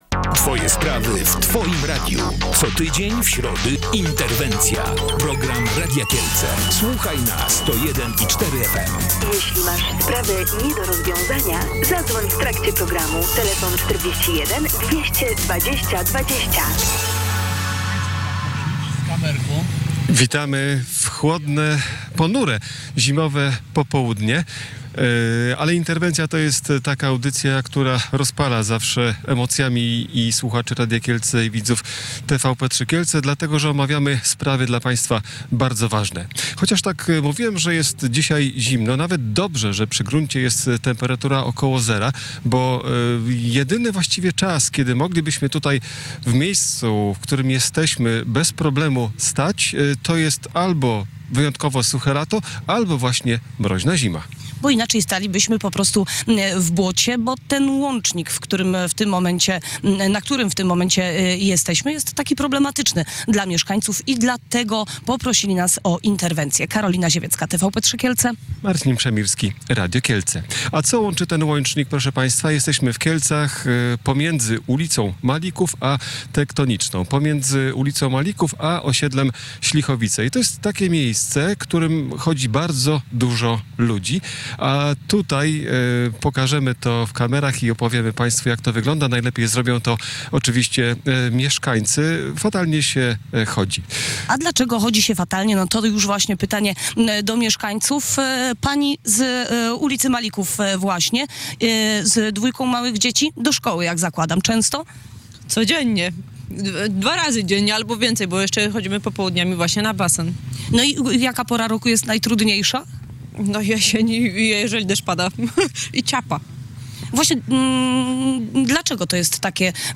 O tym będziemy rozmawiać w programie Interwencja.